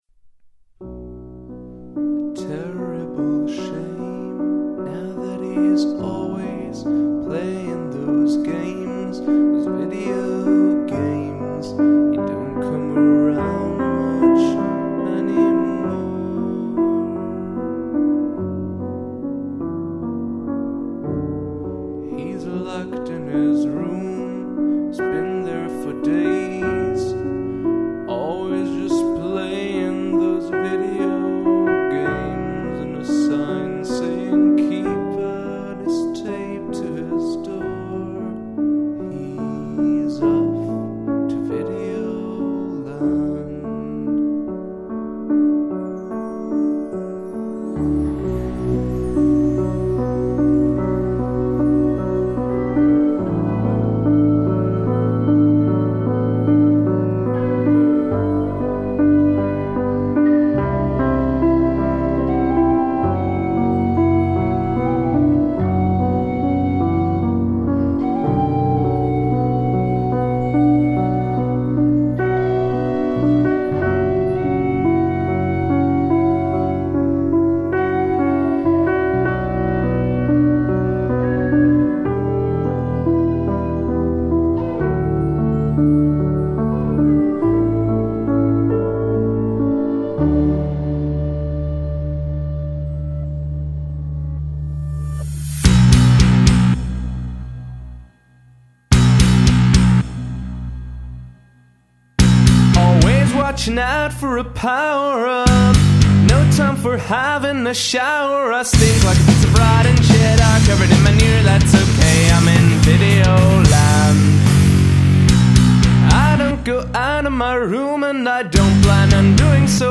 Must include a classical music sample